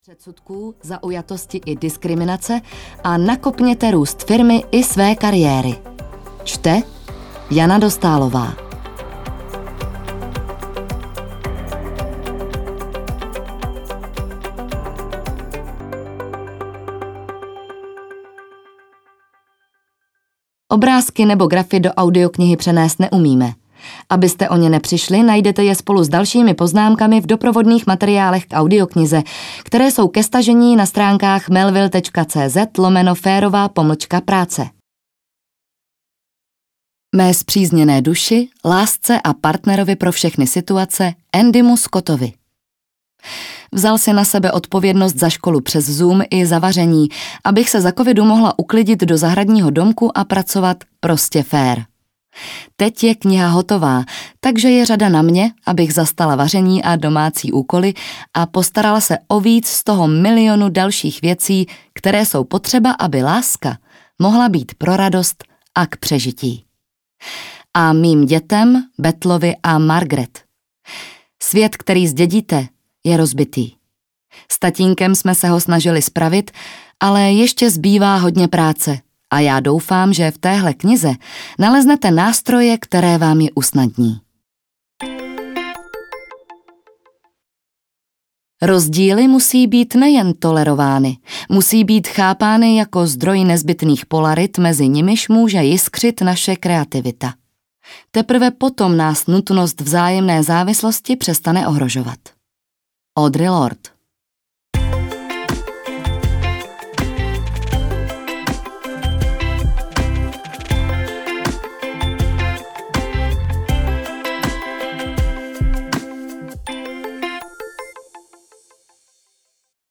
Férová práce audiokniha
Ukázka z knihy